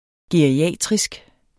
Udtale [ geɐ̯iˈæˀtʁisg ]